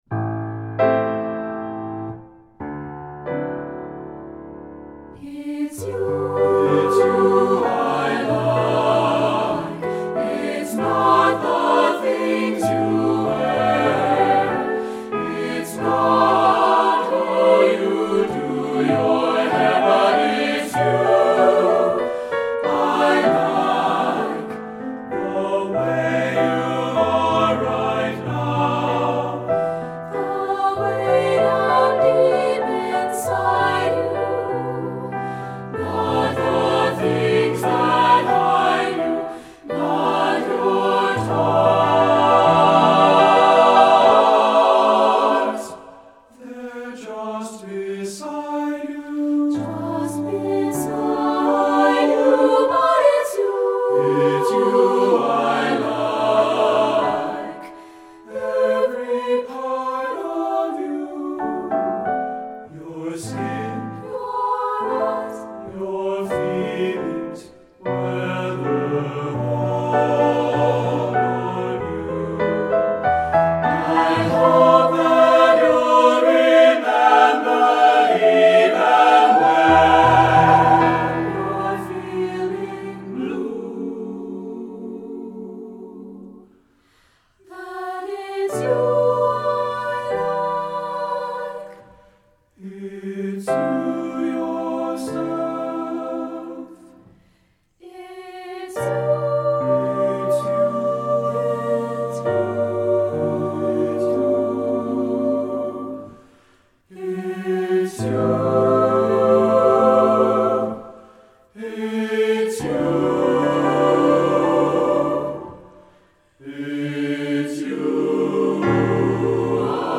Voicing: Mixed Voices